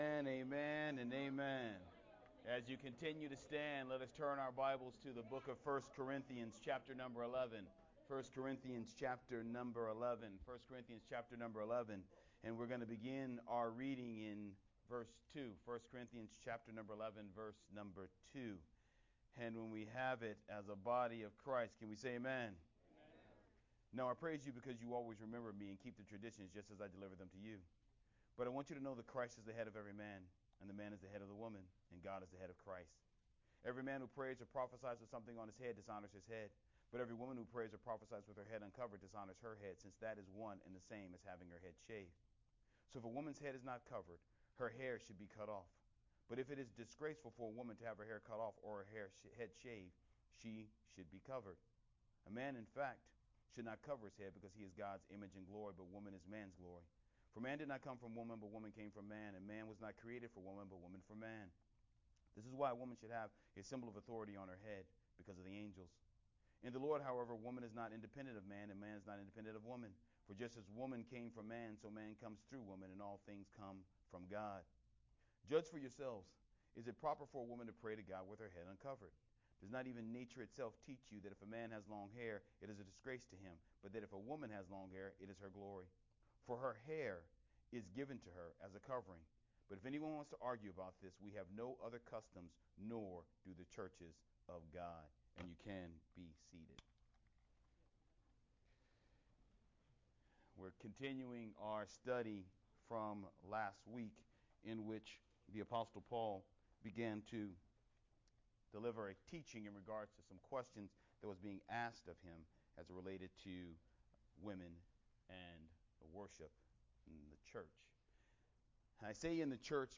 Sermon From 1st Corinthians 11:2-16